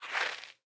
Minecraft Version Minecraft Version snapshot Latest Release | Latest Snapshot snapshot / assets / minecraft / sounds / block / powder_snow / step1.ogg Compare With Compare With Latest Release | Latest Snapshot